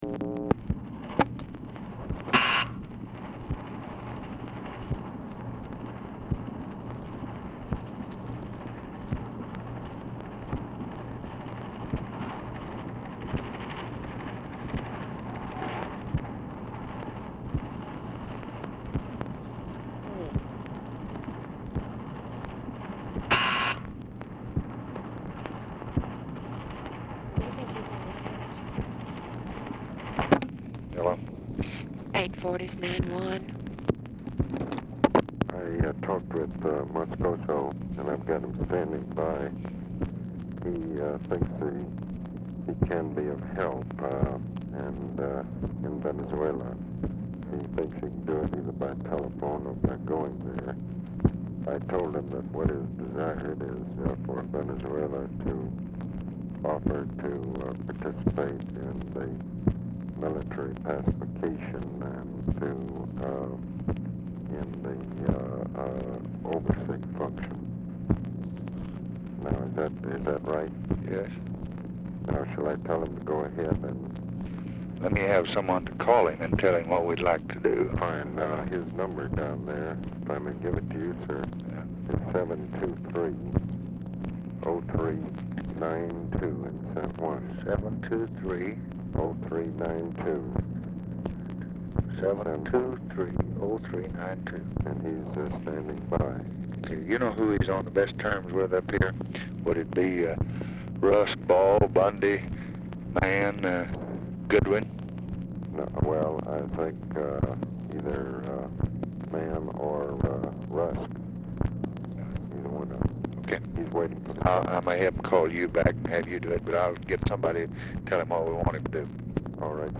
Telephone conversation # 7411, sound recording, LBJ and ABE FORTAS, 4/30/1965, 12:01PM | Discover LBJ
FORTAS ON HOLD 0:35
Format Dictation belt
Location Of Speaker 1 Oval Office or unknown location